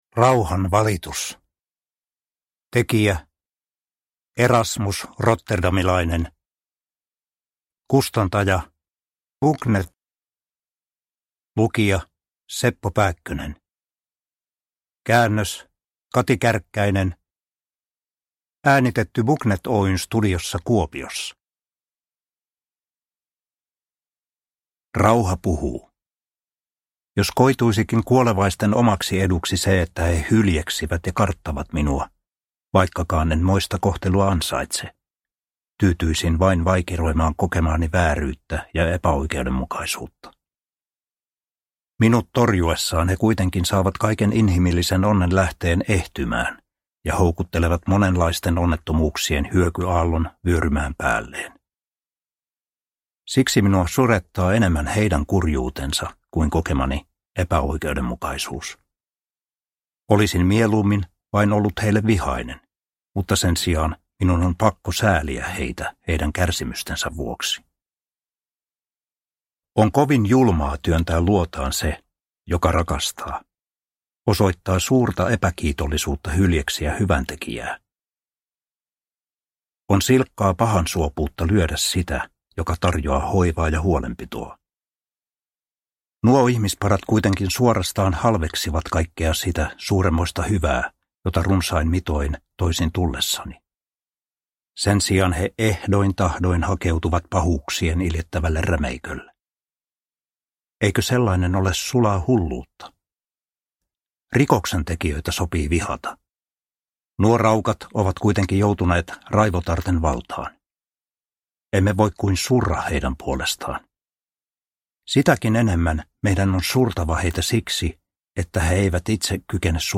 Rauhan valitus – Ljudbok